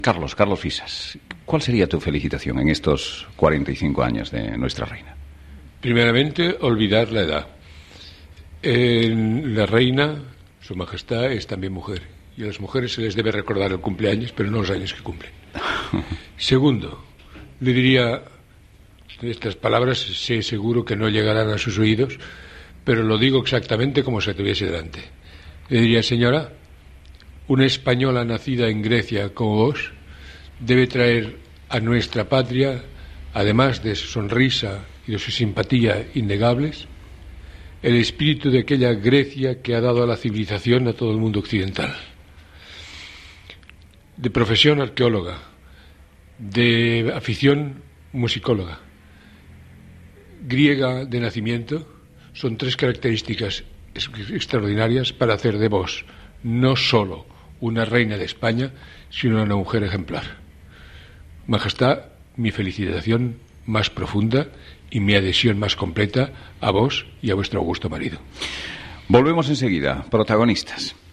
Info-entreteniment